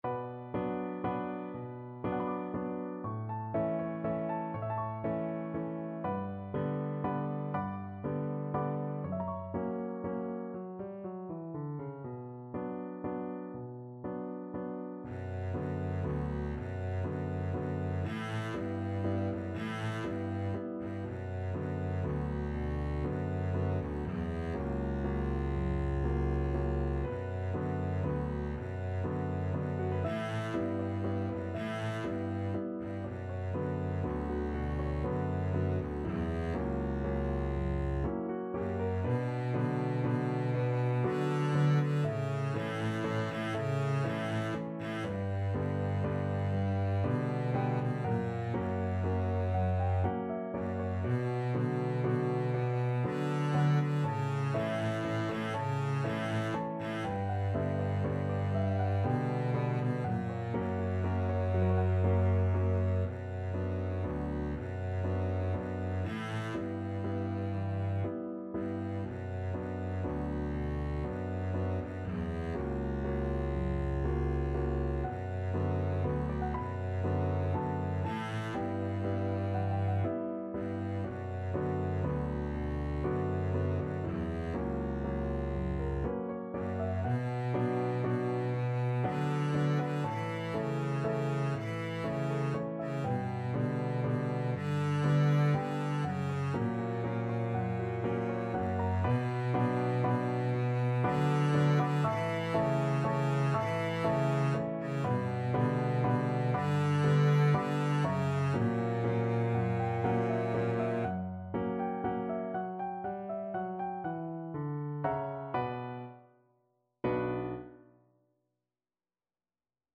Double Bass version
Slow Waltz .=40
3/4 (View more 3/4 Music)